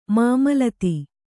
♪ māmalati